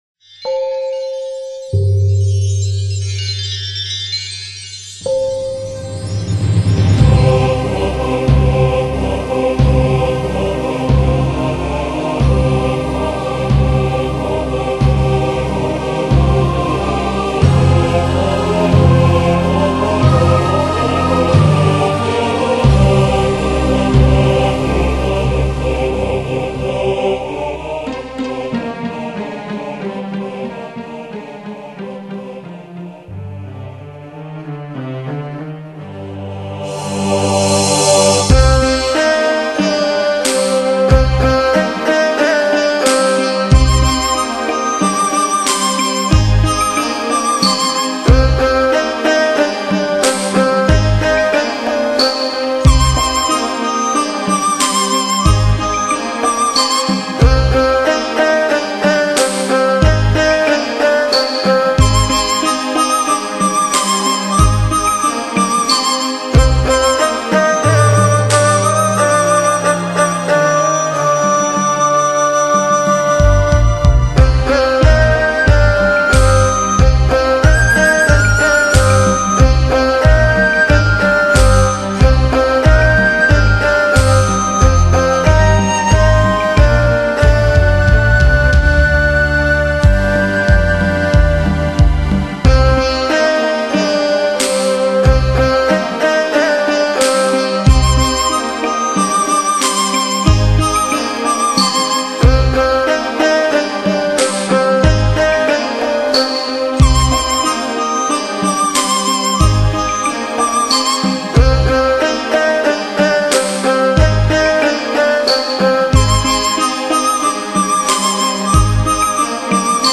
精挑细选的养心音乐，
能让您安神定意，舒展身心，